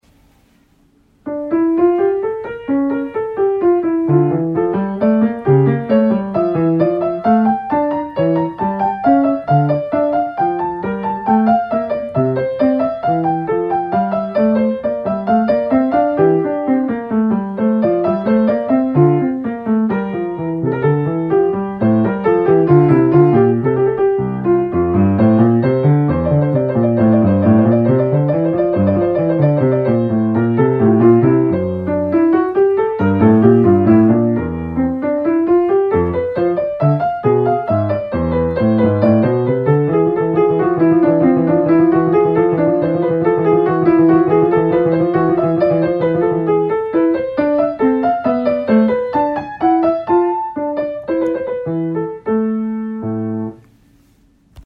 Quick voice memo recording: